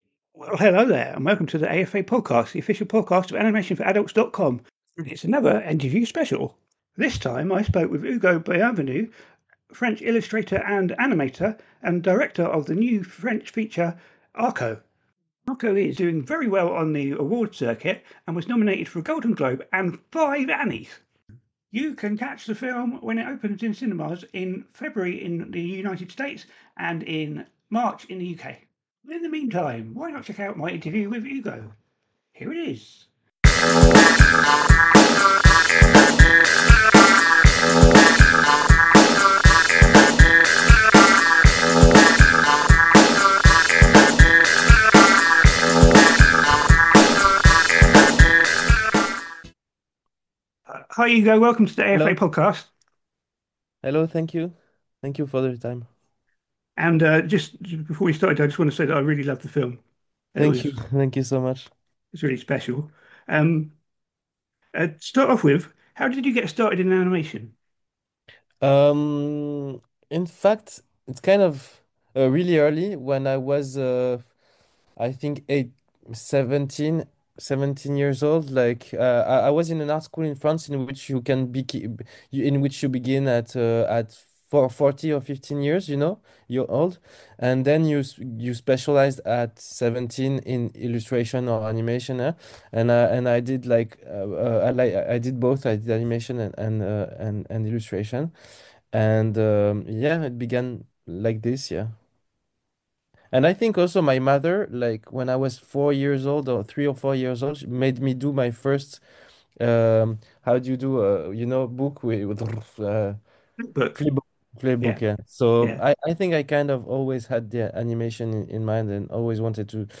The AFA Podcast Interview